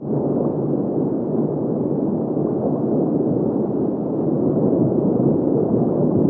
DEEP UNDER06.wav